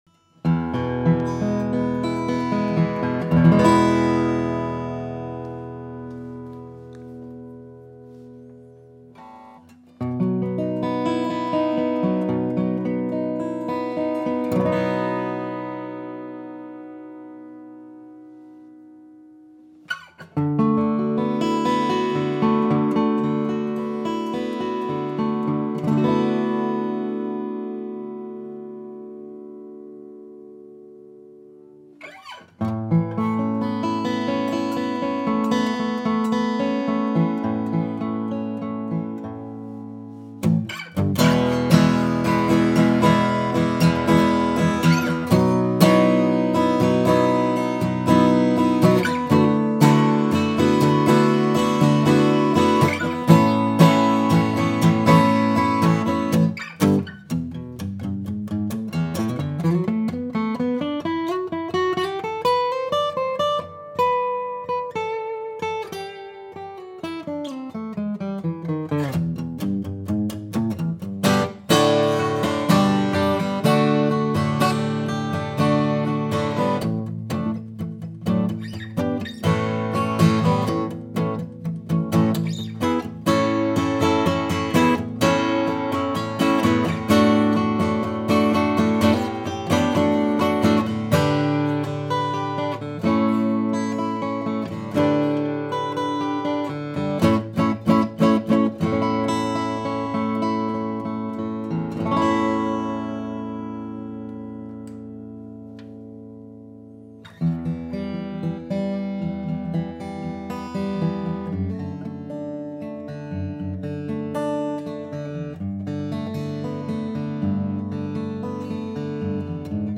Feels and sounds like a true vintage Martin.
This is the non-VTS version, which offers awesome tone that has developed for over 10 years which is instantly heard in her voice: open, balanced, sweet high notes and a clear fundamental base.
When it comes to tone, she had me at the first strum, because of its ringing purity, impressive depth, effortless volume, and her expansive, open, room-filling presence. When it comes to dynamics, it sounds lovely when played with a very light touch. The clear and pretty ring of the barely-picked notes provides as much Wow Factor as the thrilling power that leaps from the sound hole when played full out.